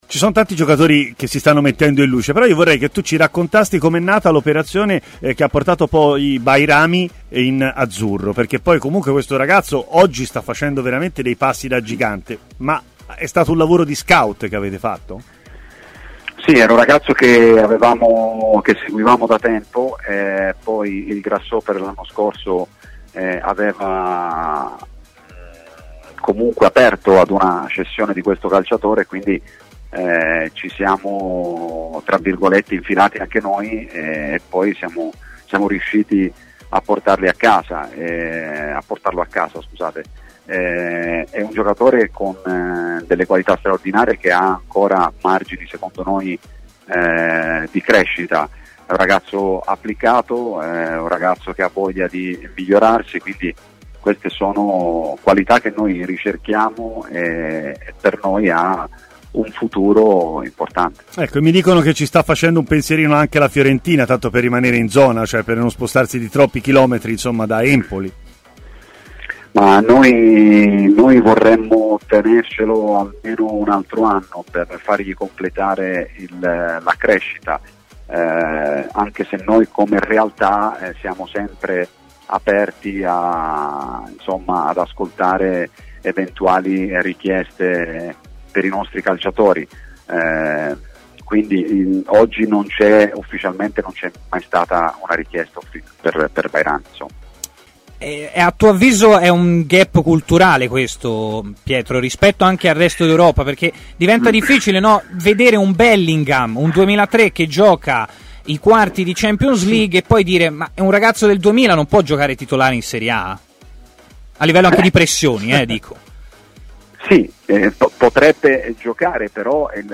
Queste alcune delle sue dichiarazioni: